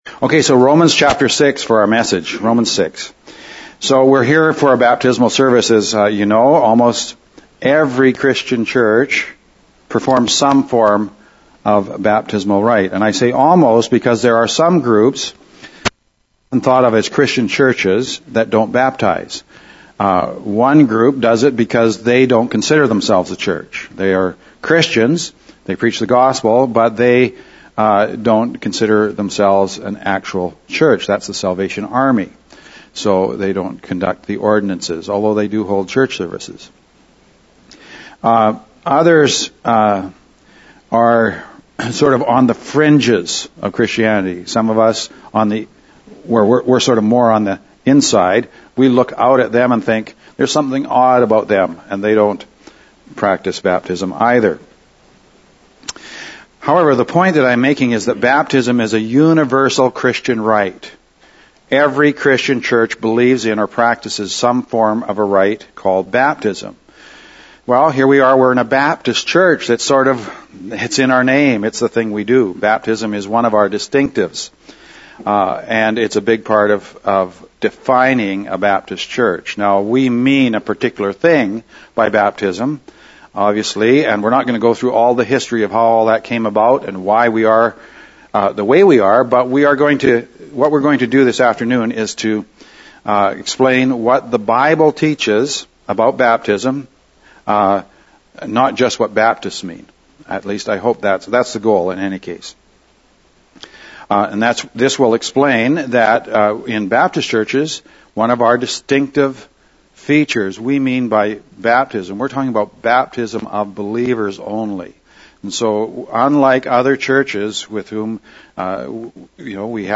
Romans 6.3-4 Our service was a baptismal service. We took the time to explain clearly what the symbolism of baptism means, according to the Scriptures.